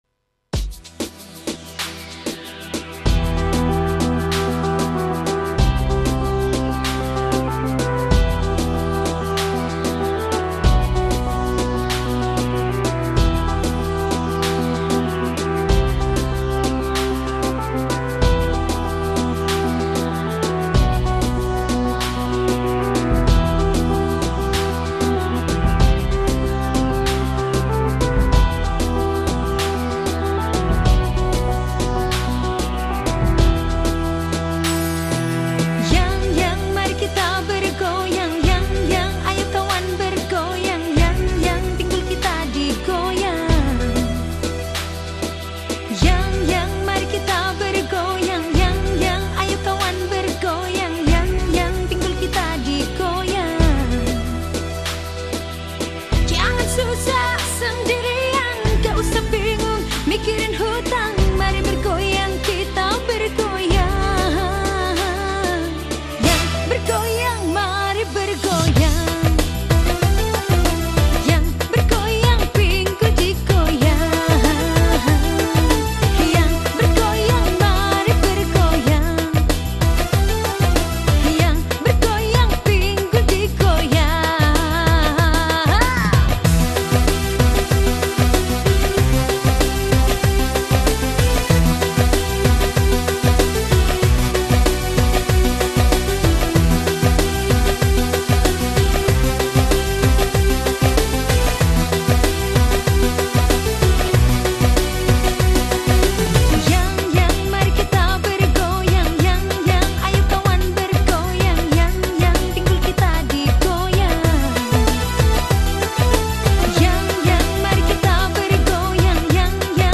penyanyi Dangdut